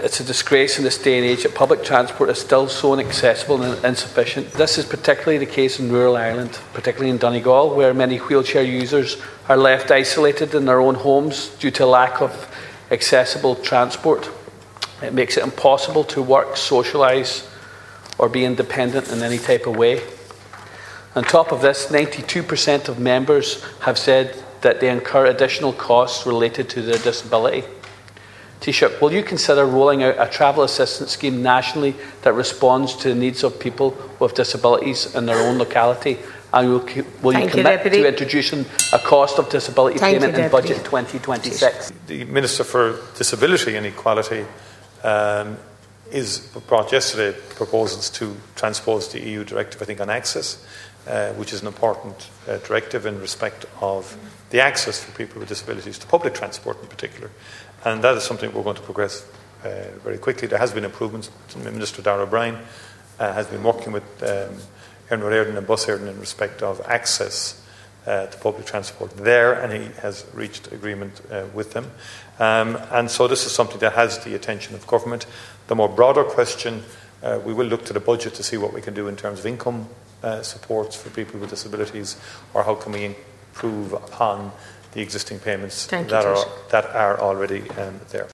Ward raises issues facing wheelchair users in the Dail chamber
In particular, Deputy Ward told Taoiseach Michael Martin that access and public transport are still major issues……….